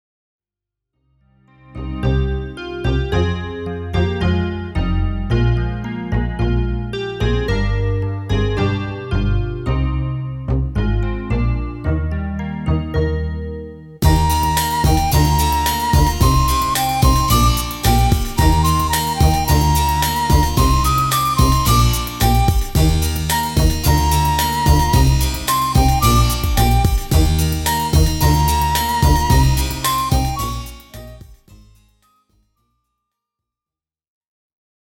- Instrumental (+0.99 EUR)